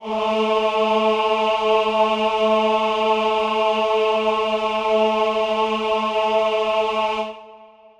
Choir Piano
A3.wav